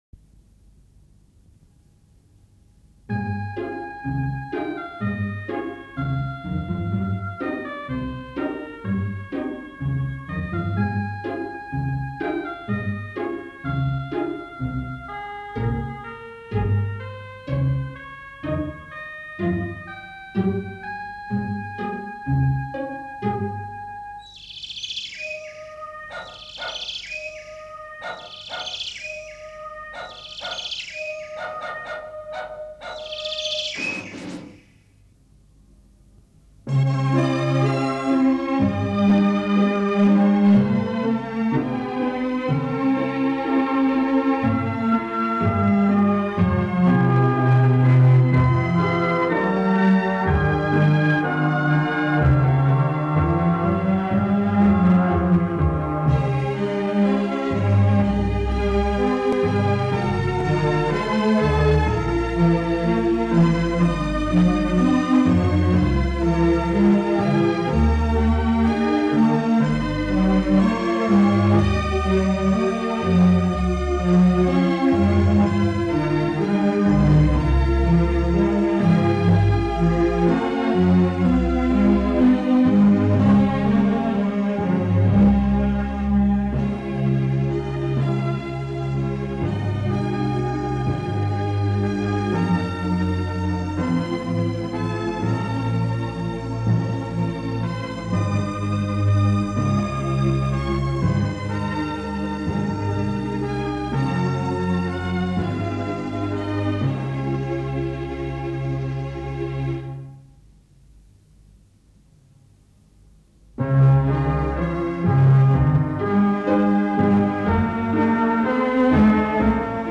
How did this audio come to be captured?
It has words, but I shall have to print them out. I will clean up the sound between the verses and put it in mp3 format.